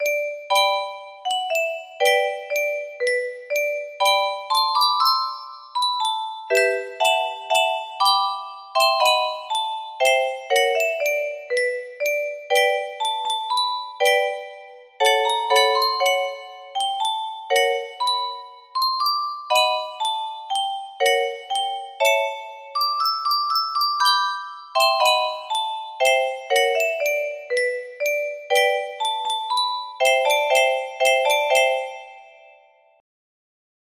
애국가 music box melody
Grand Illusions 30 (F scale)